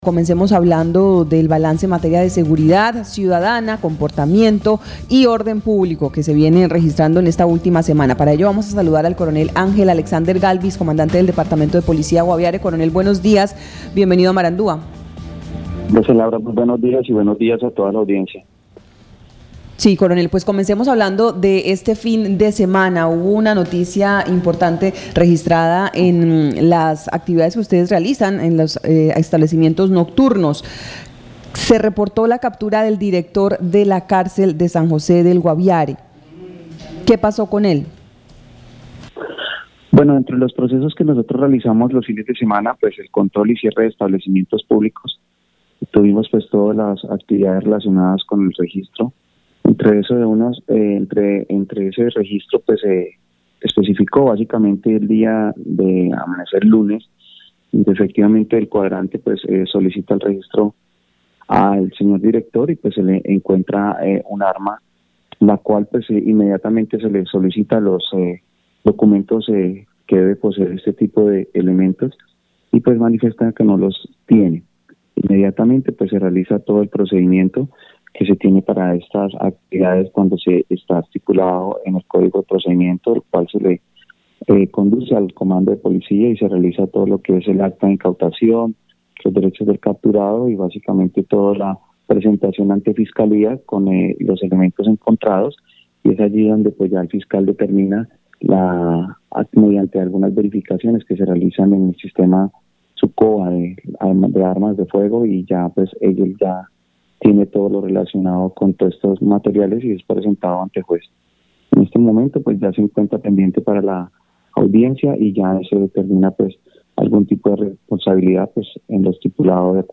Escuche a Coronel Ángel Alexander Galvis Ballén, comandante Departamento de Policía Guaviare.